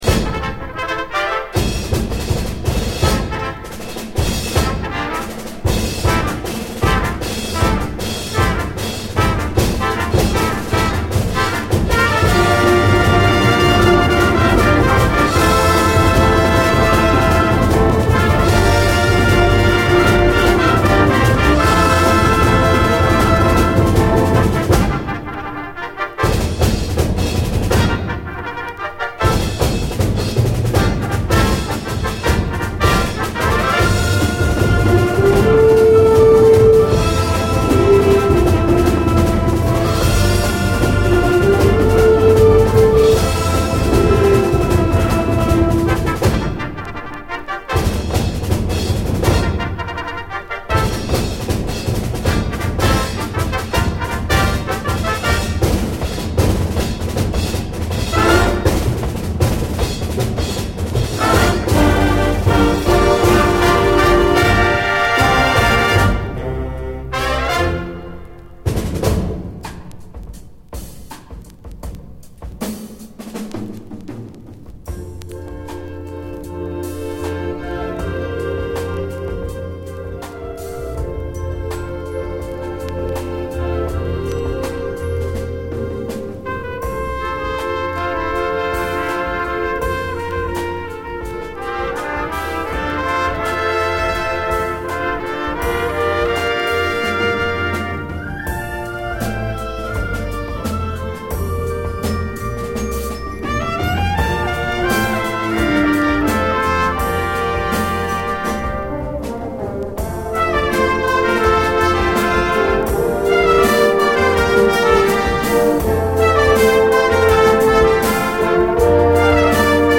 SOUL, JAZZ FUNK / SOUL JAZZ, 70's～ SOUL, JAZZ